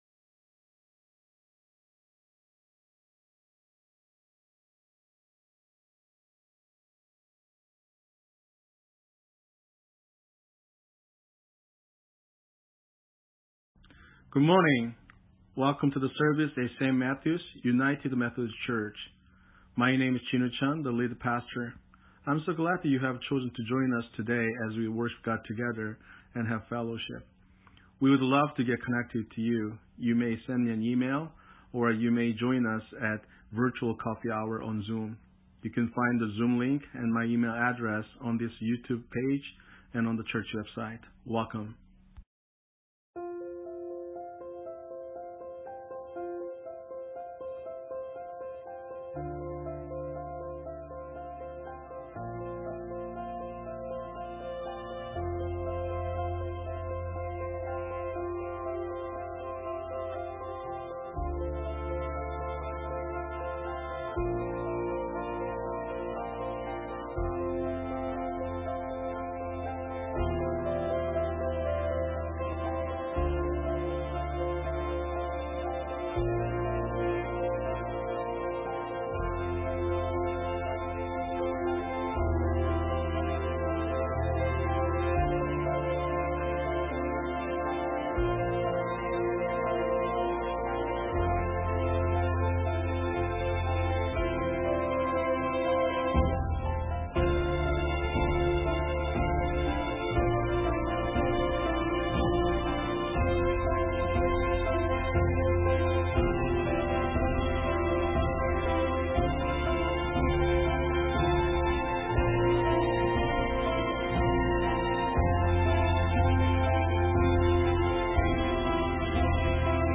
Sermon:Let God's Will Be Done on Earth (Earth Day) - St. Matthews United Methodist Church
The actual worship service begins 15 minutes into the recordings.